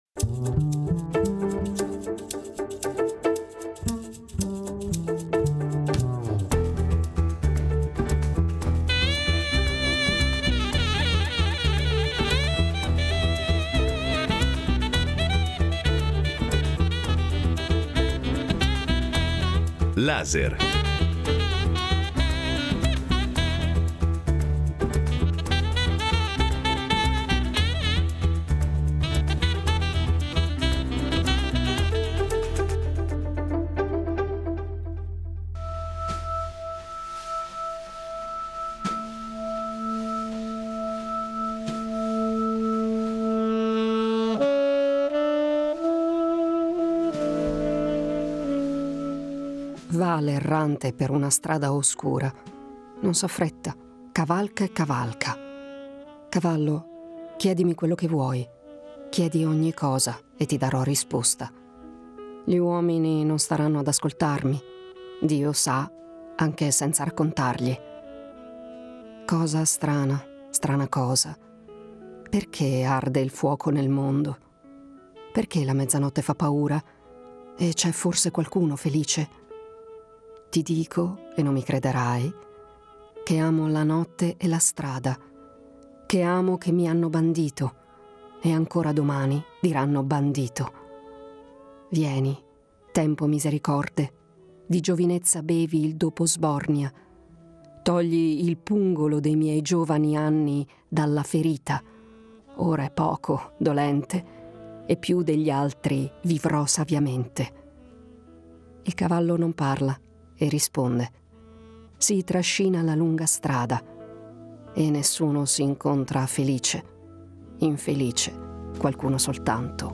Olga Sedakova , insignita di innumerevoli premi internazionali accetta di parlare della guerra in Ucraina e lo fa da Mosca in un’intervista a "Laser" in cui non risparmia le critiche all’establishment russo.